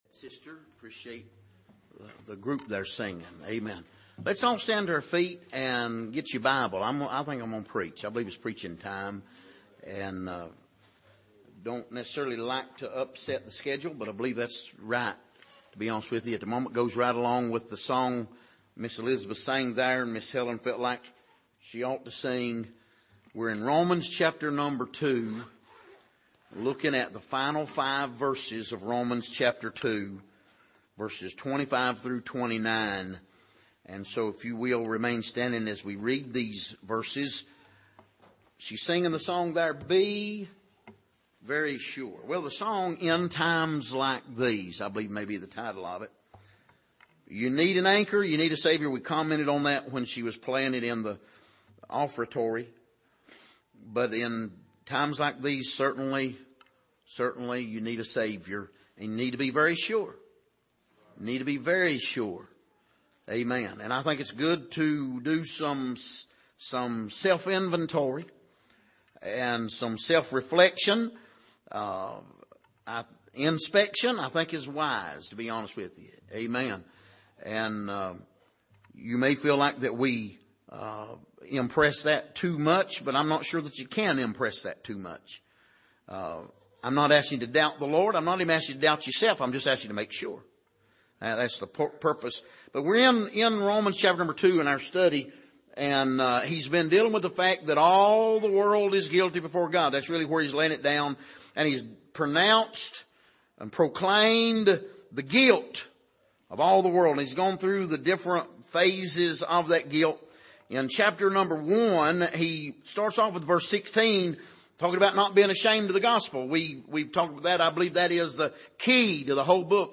Passage: Romans 2:25-29 Service: Sunday Morning